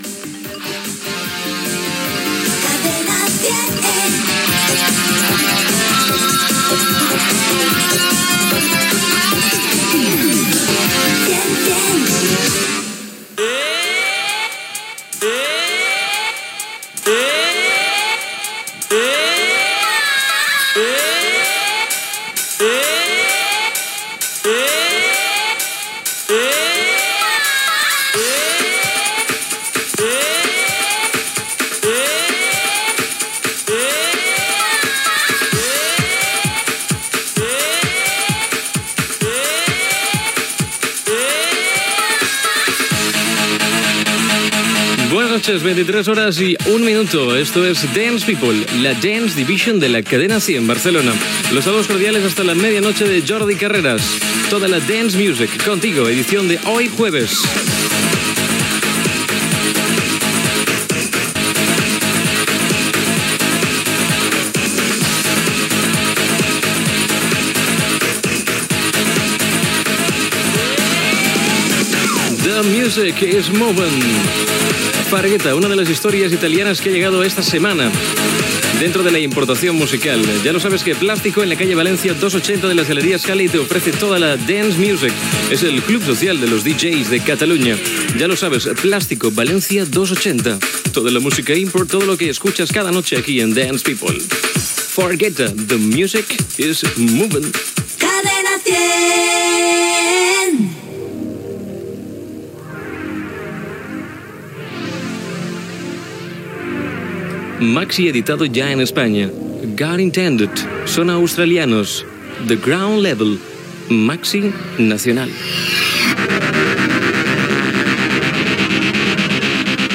Indicatiu de la cadena, hora, presentació, tema musical, publicitat, indicatiu, tema musical
Musical